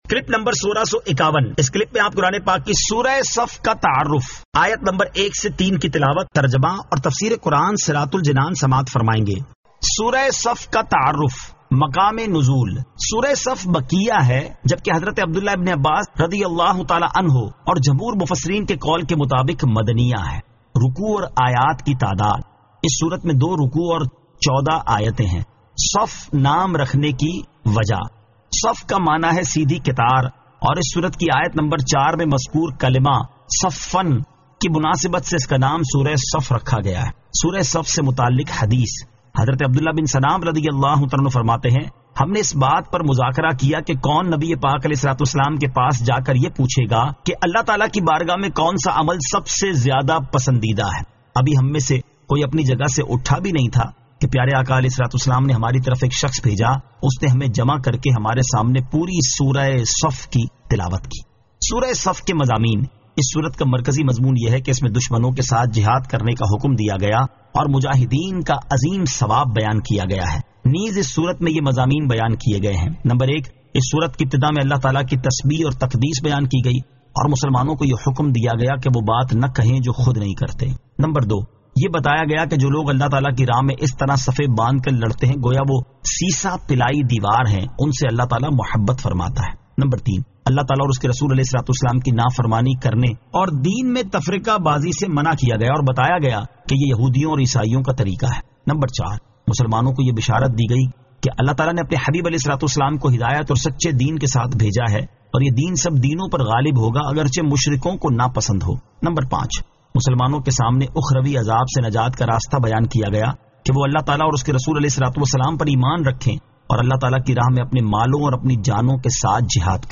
Surah As-Saf 01 To 03 Tilawat , Tarjama , Tafseer